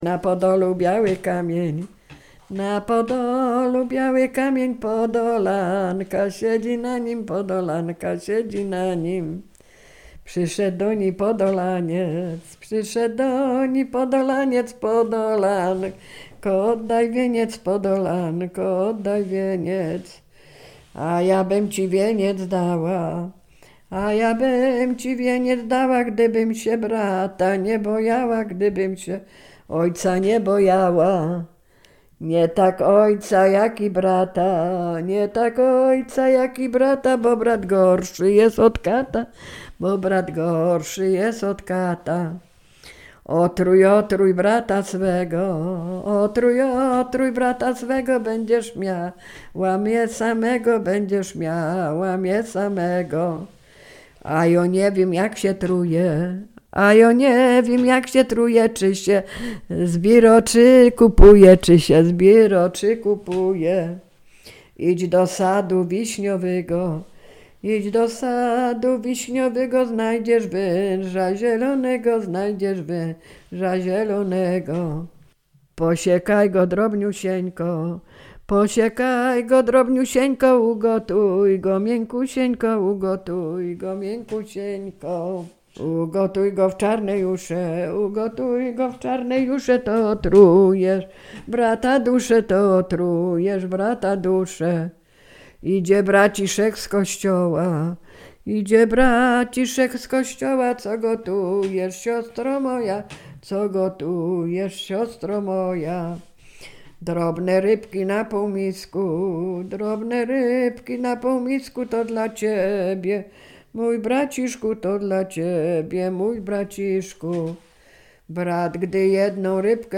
Sieradzkie
Ballada
ballady dziadowskie